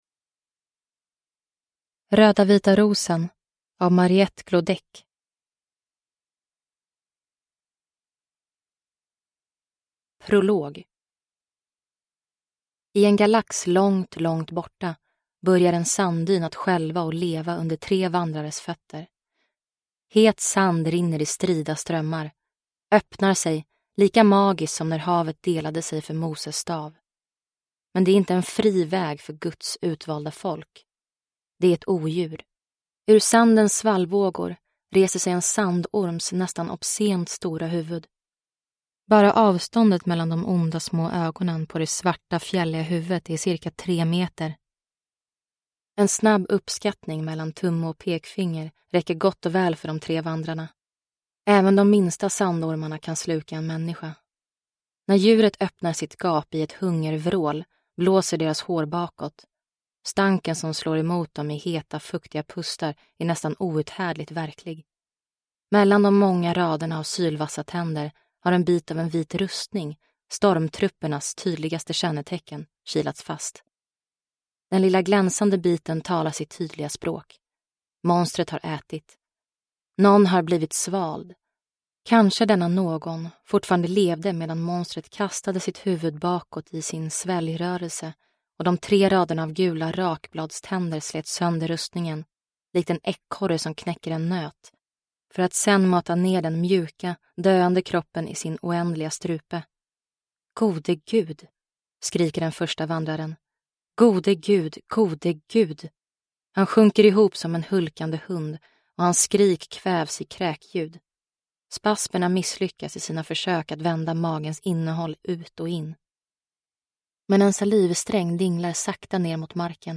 Röda Vita Rosen ; Till Pest från Kolera ; Med kärlek. – Ljudbok – Laddas ner